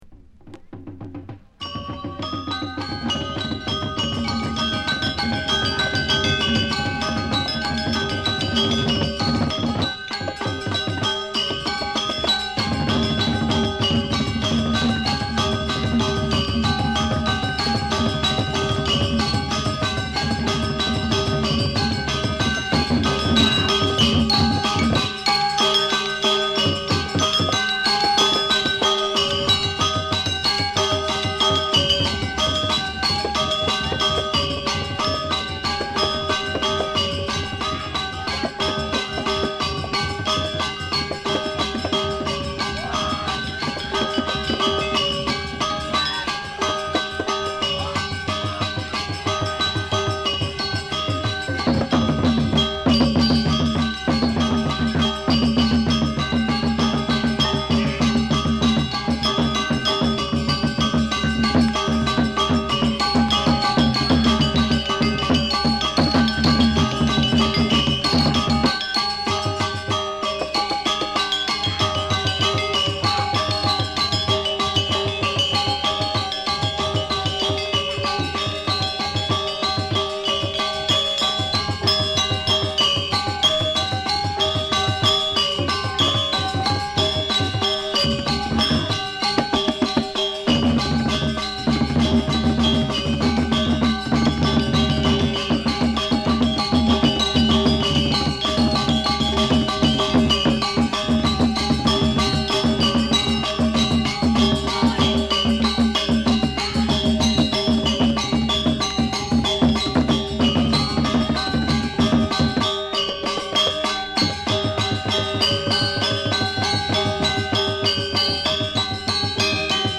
ガムランを中心とした全10曲。金属音や木琴の反復がミニマルに響く抑揚と熱量でトリップ感もある傑作です。